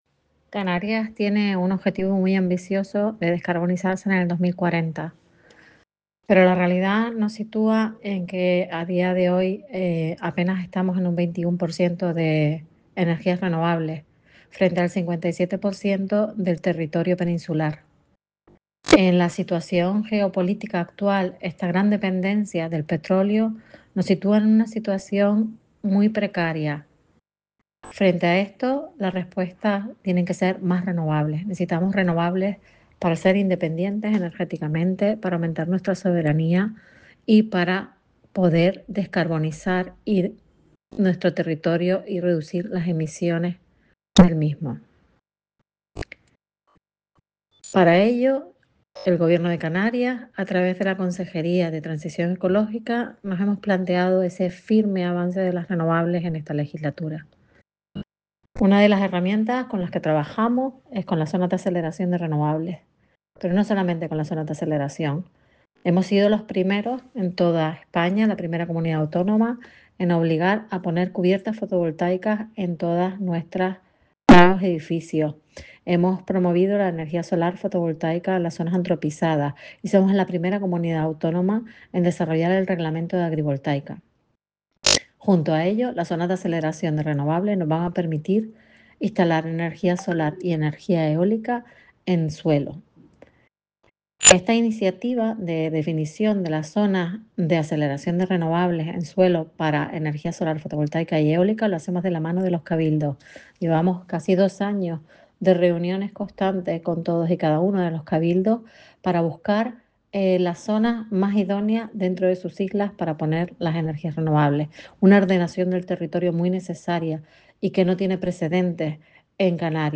Declaración en audio de Julieta Schallenberg, viceconsejera de Transición Ecológica y Energía: